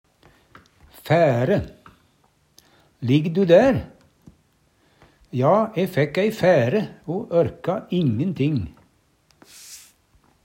fære - Numedalsmål (en-US)